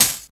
59 DIRTY HAT.wav